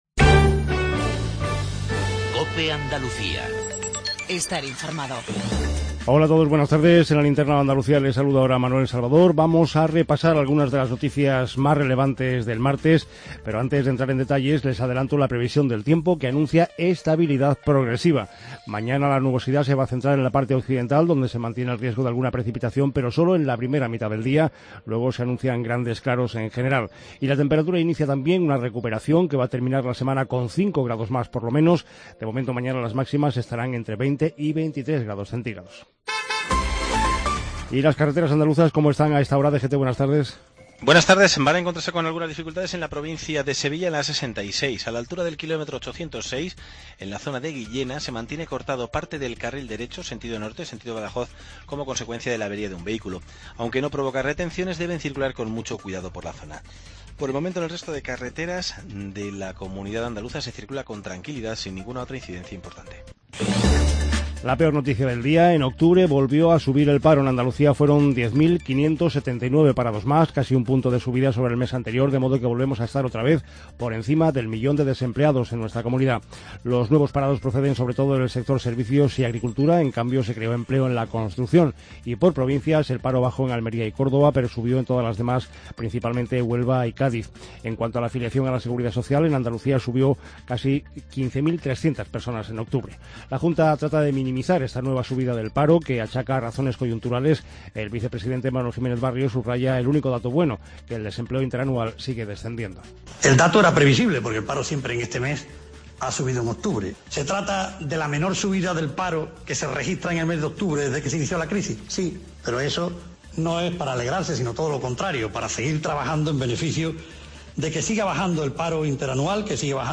INFORMATIVO REGIONAL TARDE COPE ANDALUCIA